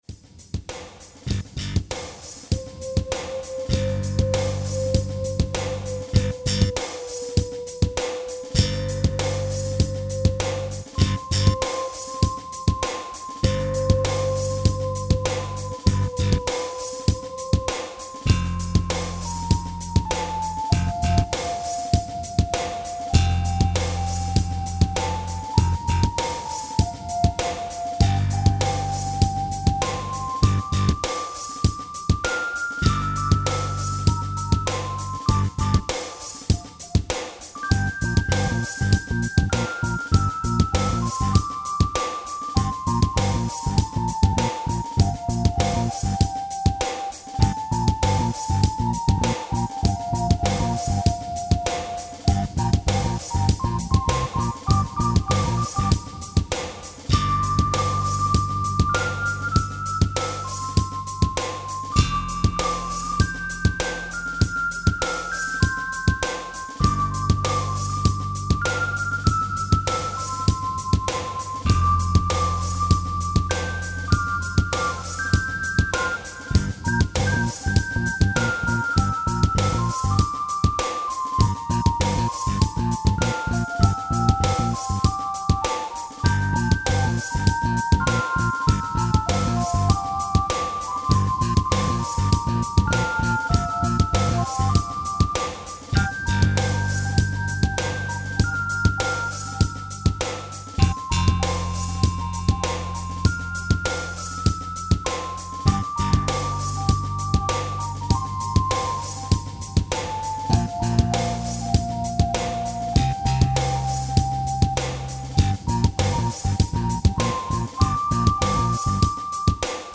Tempo: 97 bpm / Date: 28.11.2017